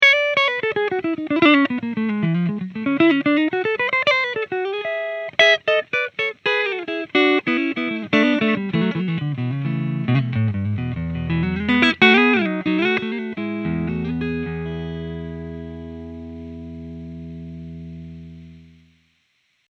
Clean riff 1